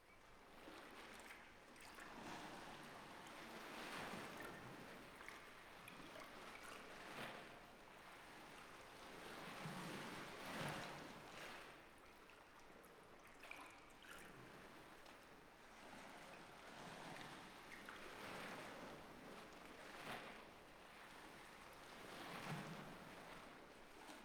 waves-slow.ogg